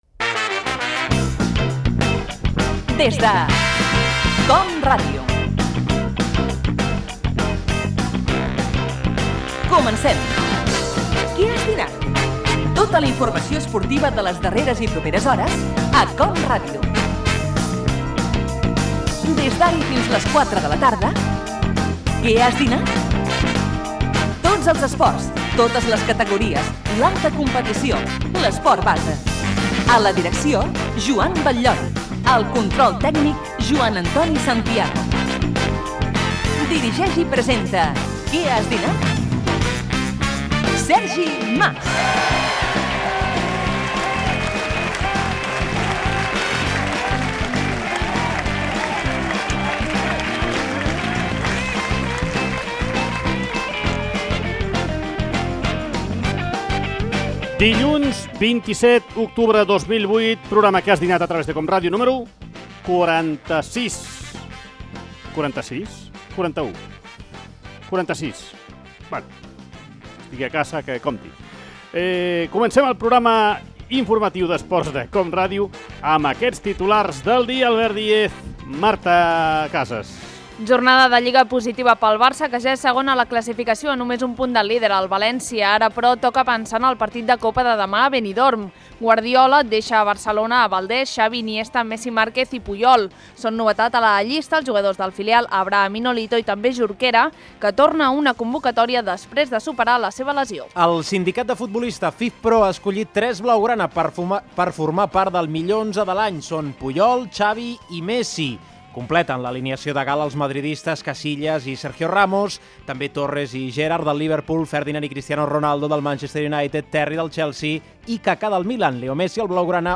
Careta del programa, presentació i titulars esportius
Esportiu
FM